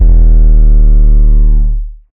DDW2 808 4.wav